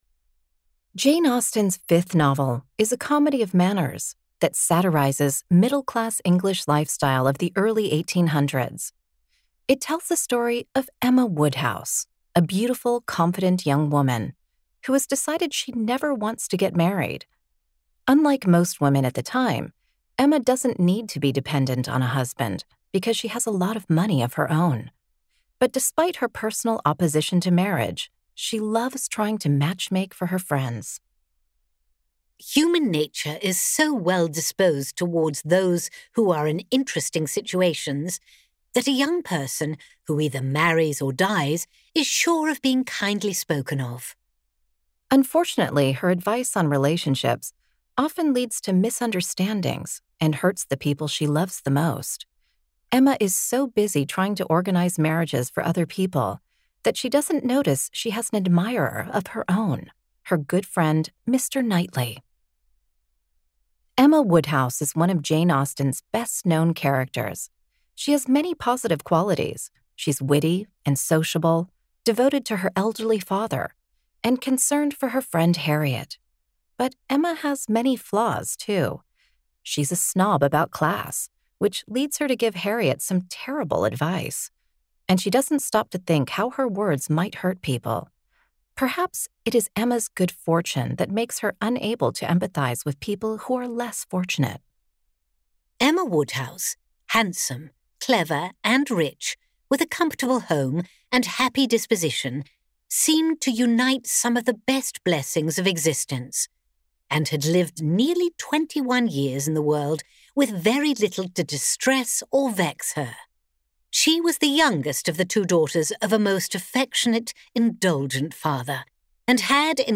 Speaker (American accent)
Speaker (UK accent)